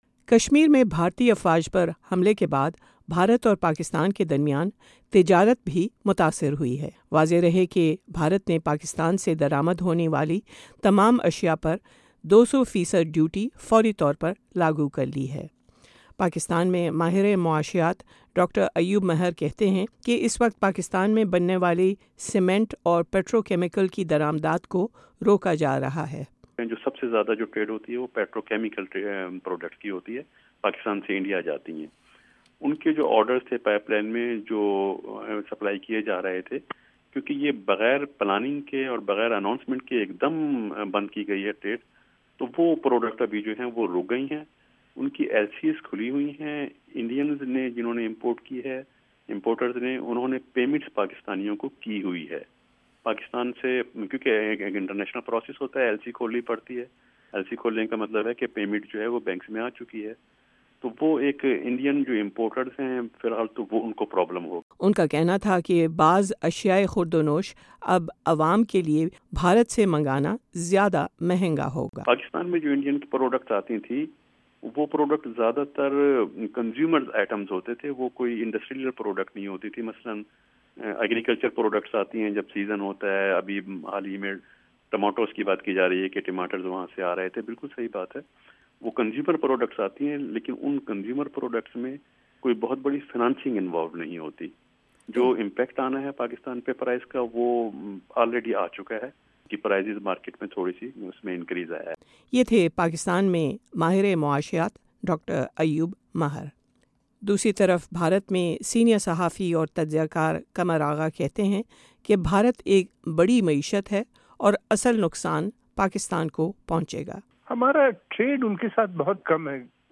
VOA discussion program: Economic fallout of Indo-Pakistan tension